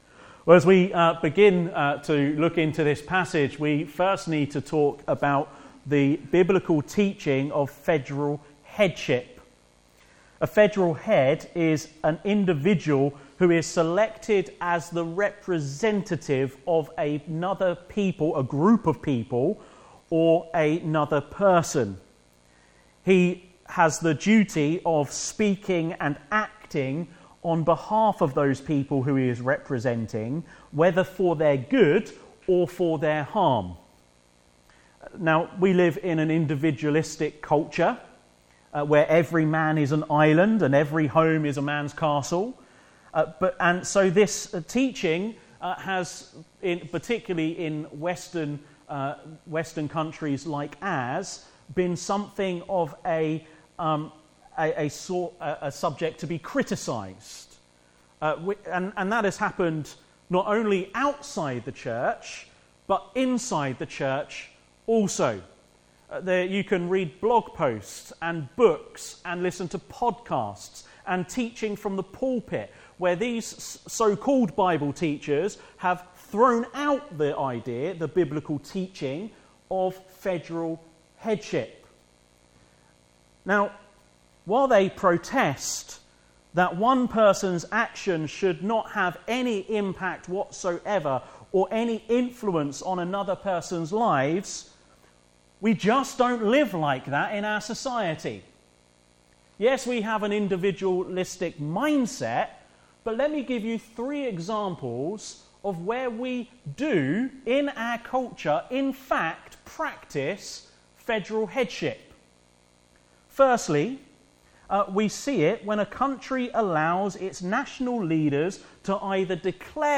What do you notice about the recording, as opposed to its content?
5-10 Service Type: Afternoon Service Special Service Did Jesus Really Rise From the Dead?